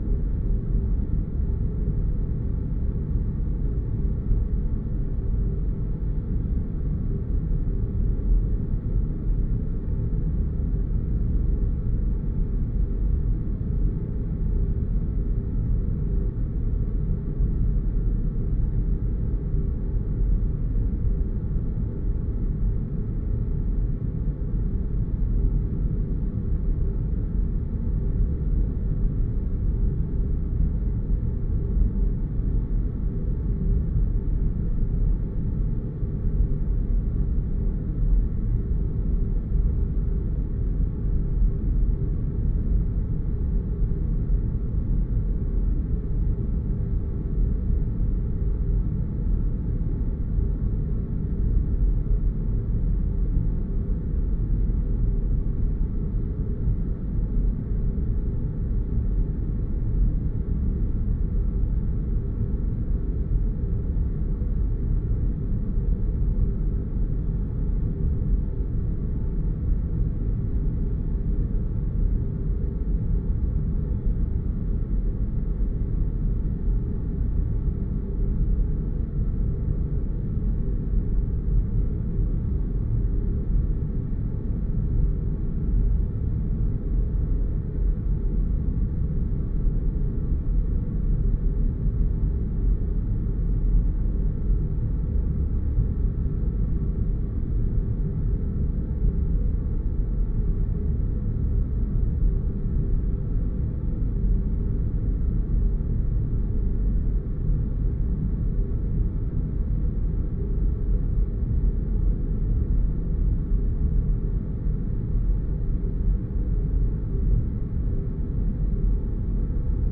underground_3.ogg